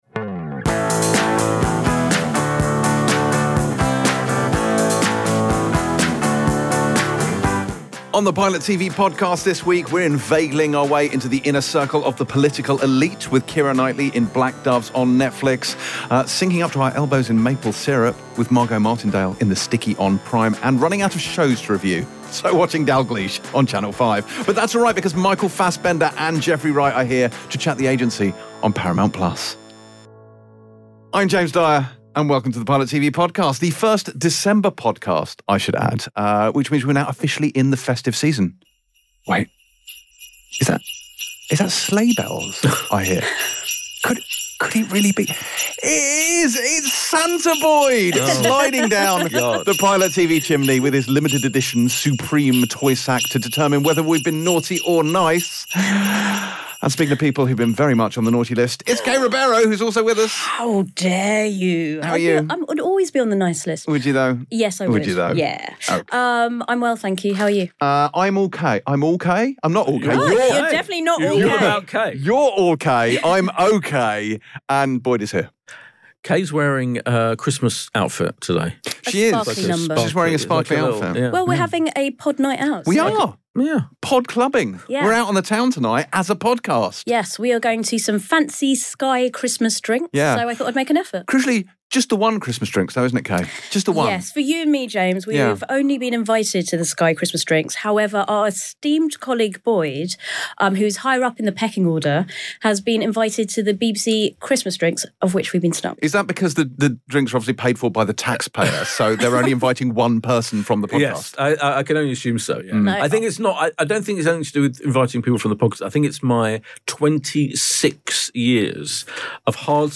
#315 Black Doves, The Sticky, and Dalgliesh. With guests Michael Fassbender & Jeffrey Wright